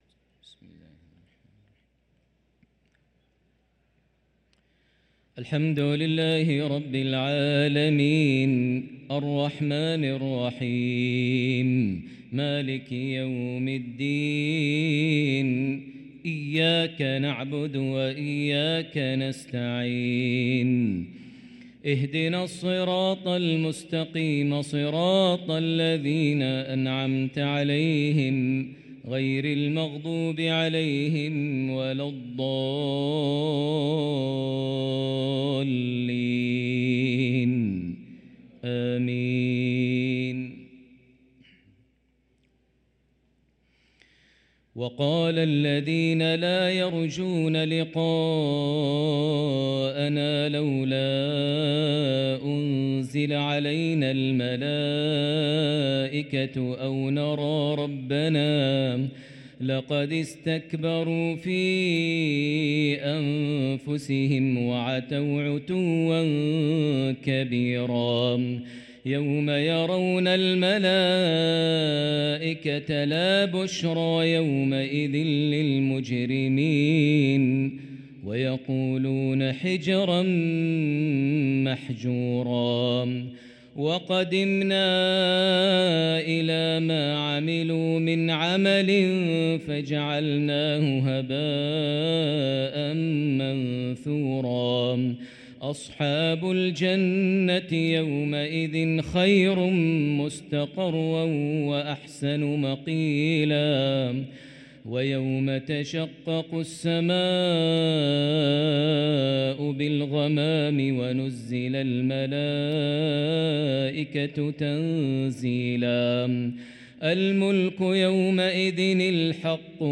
صلاة العشاء للقارئ ماهر المعيقلي 6 ربيع الأول 1445 هـ
تِلَاوَات الْحَرَمَيْن .